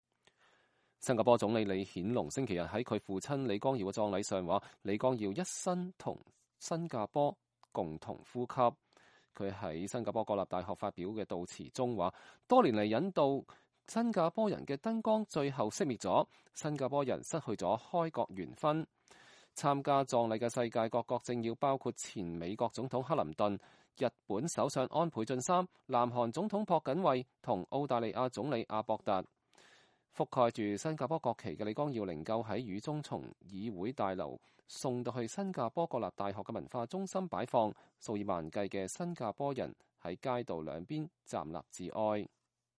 在新加坡國立大學舉行的葬禮